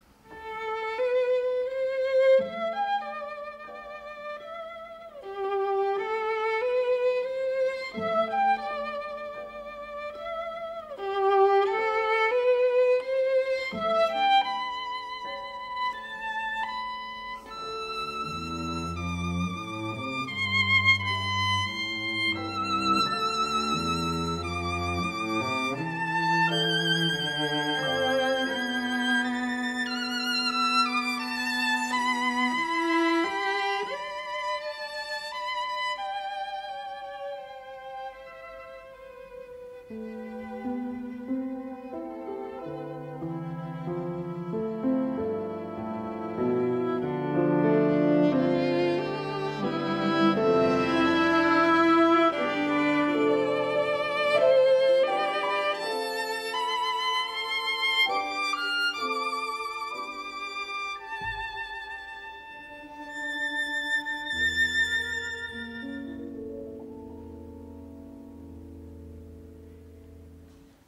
* Maurice Ravel – Sonata para violín y piano